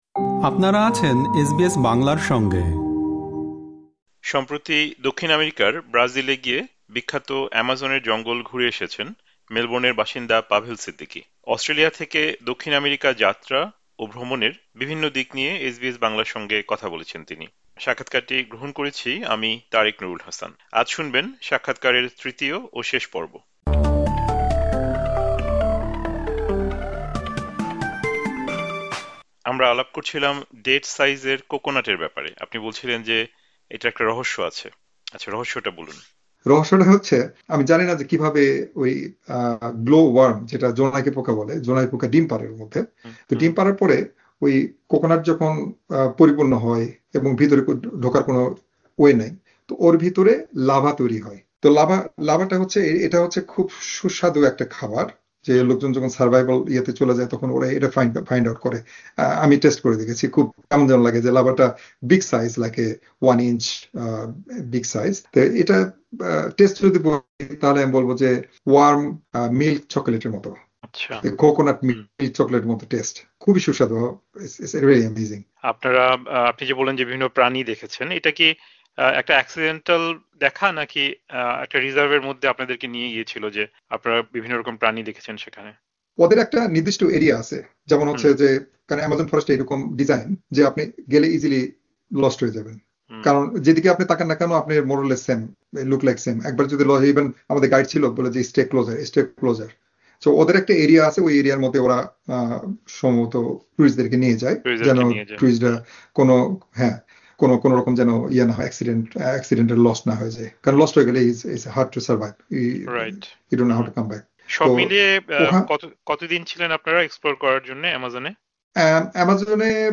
সাক্ষাৎকারটি শুনতে উপরের অডিও-প্লেয়ারে ক্লিক করুন।